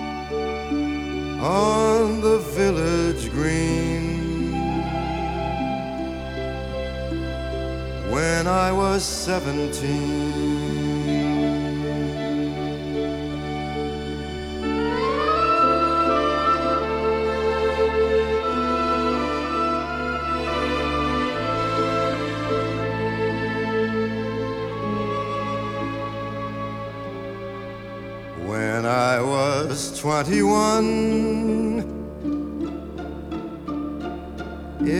Жанр: Поп музыка / Рок / Джаз